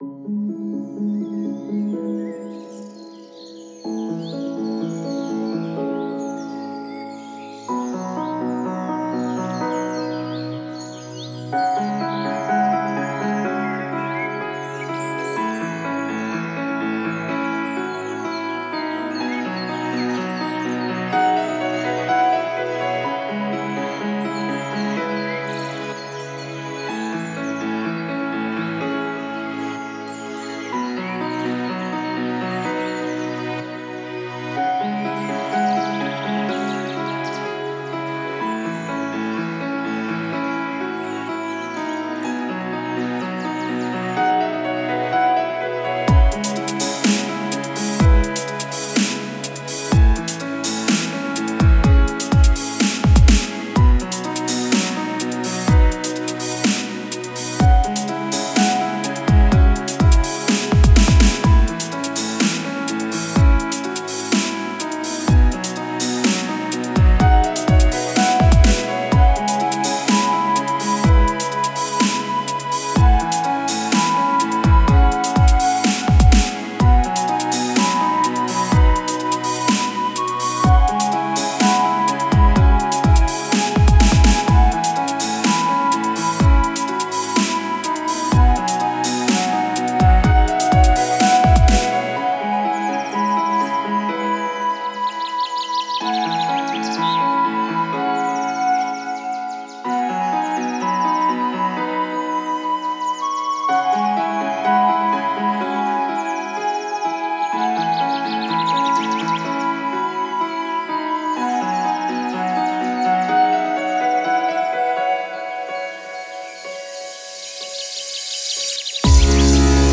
мягкими мелодиями